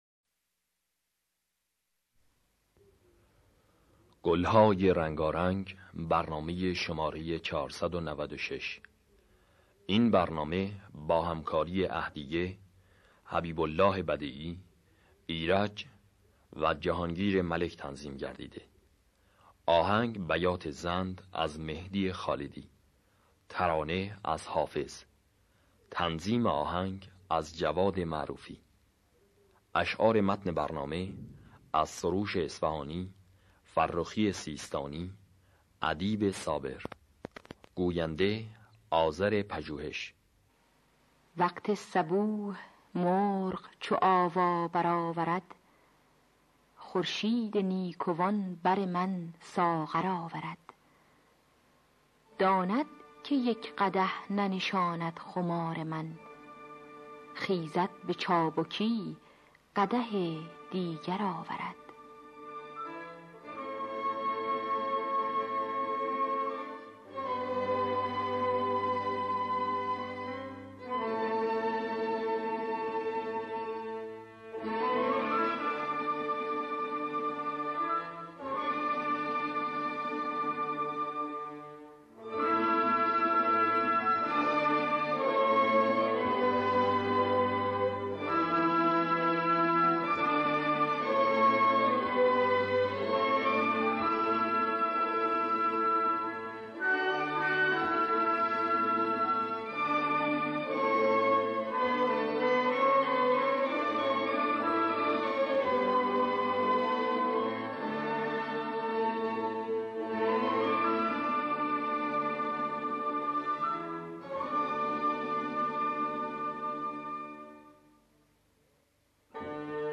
در دستگاه بیات زند